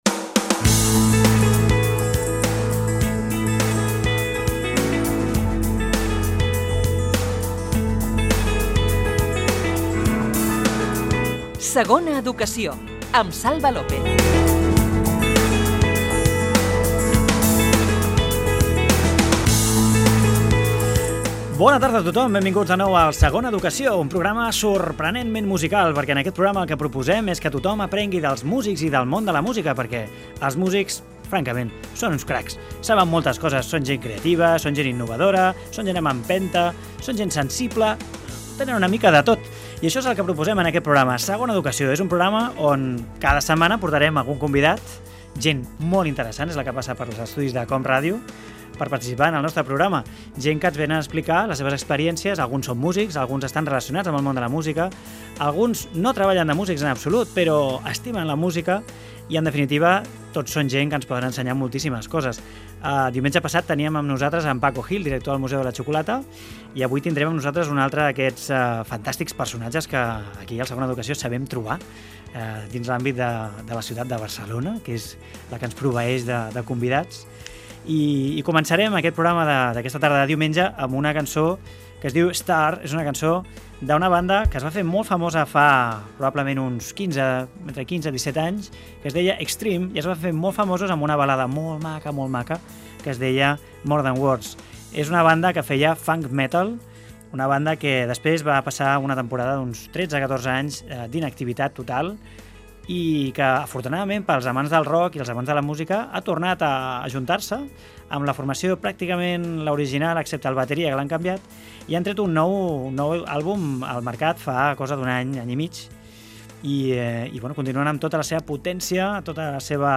Careta, inici i sumari del programa per aprendre dels músics i del món de la música
Divulgació
FM